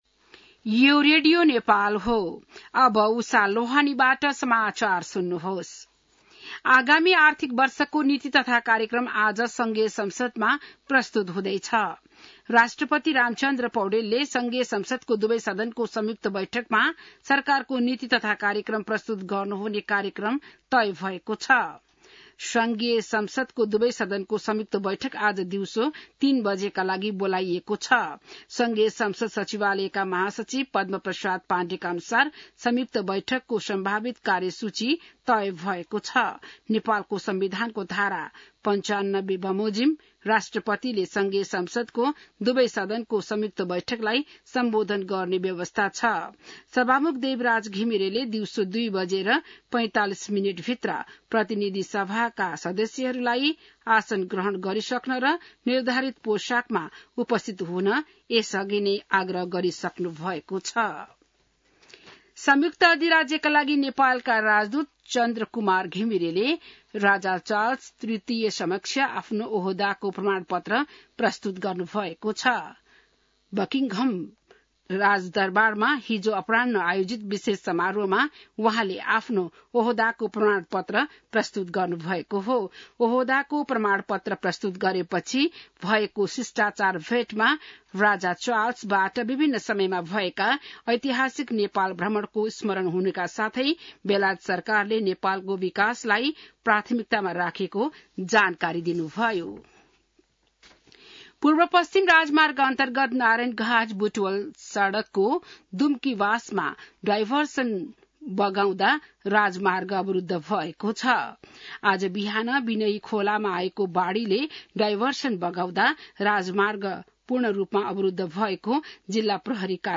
बिहान १० बजेको नेपाली समाचार : १९ वैशाख , २०८२